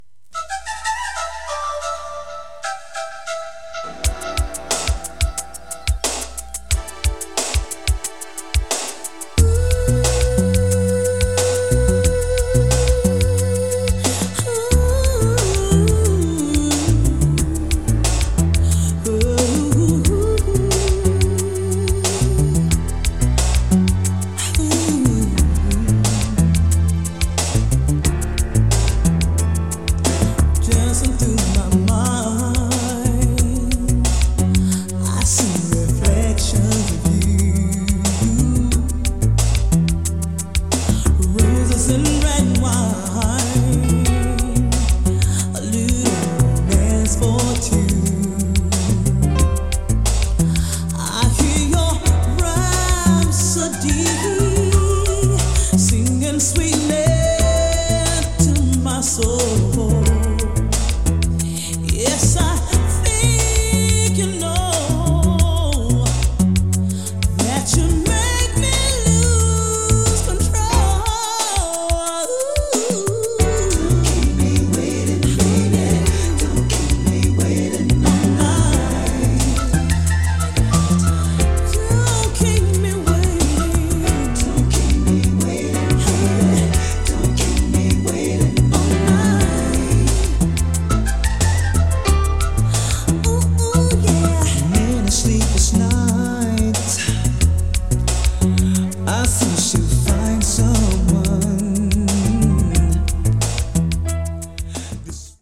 SOUL/FUNK